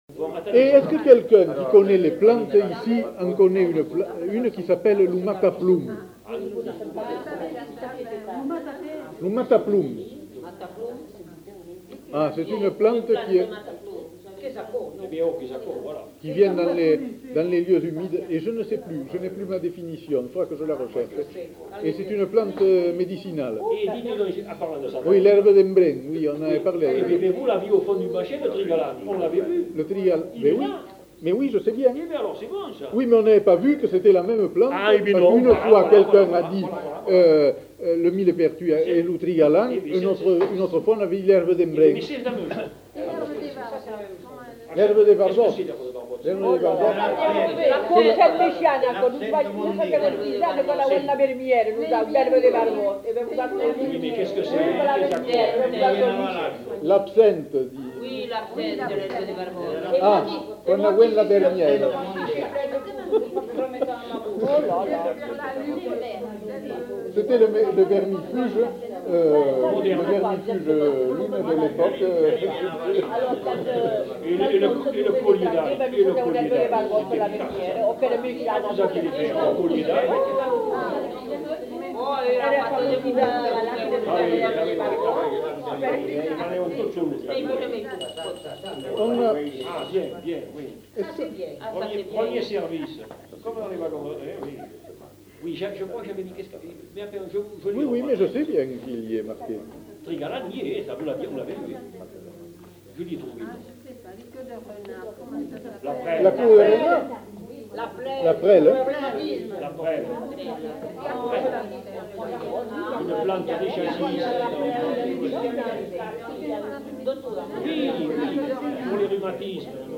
Genre : témoignage thématique